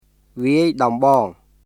[ヴィアイ・ドンボーン　viˑəi dɔmbɔːŋ]